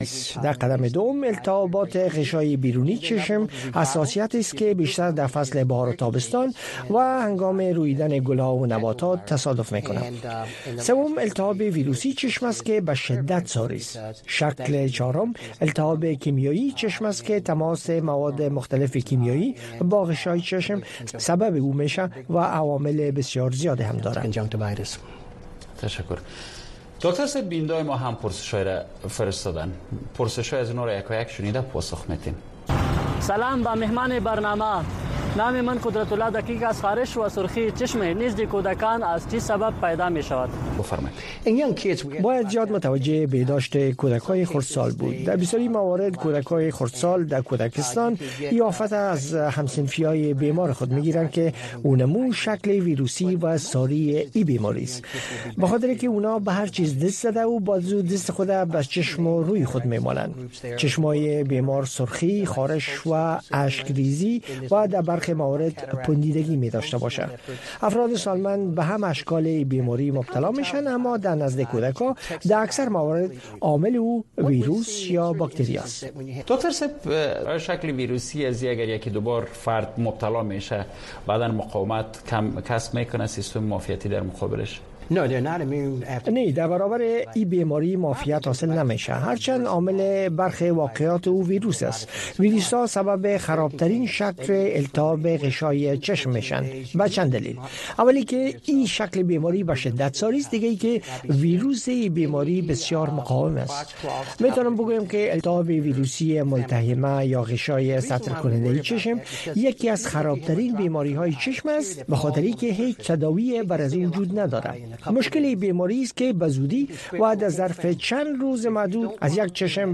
خبرونه
د وی او اې ډيوه راډيو سهرنې خبرونه چالان کړئ اؤ د ورځې دمهمو تازه خبرونو سرليکونه واورئ. په دغه خبرونو کې د نړيوالو، سيمه ايزو اؤمقامى خبرونو هغه مهم اړخونه چې سيمې اؤ پښتنې ټولنې پورې اړه لري شامل دي.